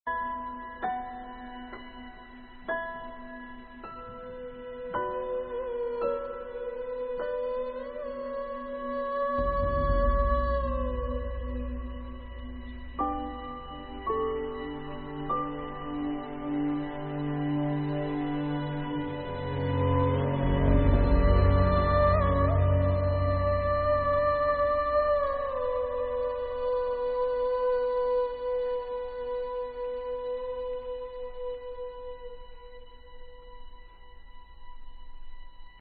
The second time the "humming" started, I recognized them...
original music off the soundtrack...